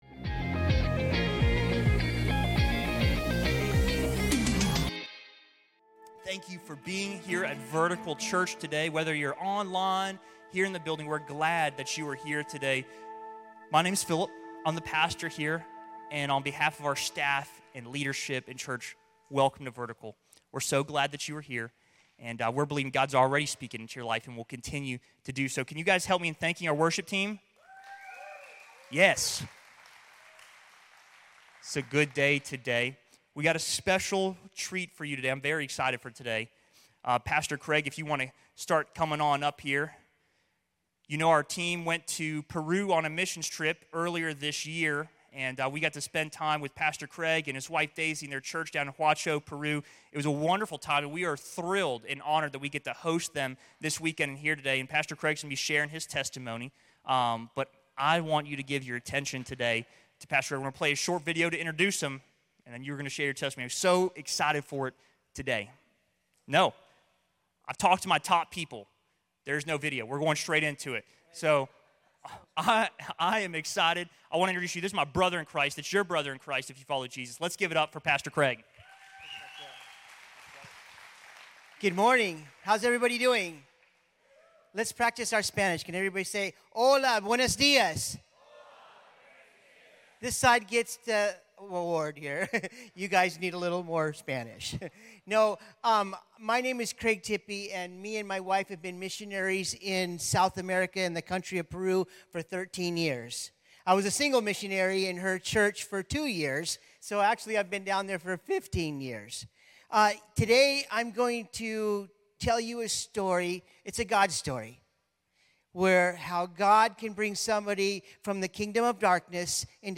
In this sermon he shares his powerful testimony and the way God has moved and continues to move in his life.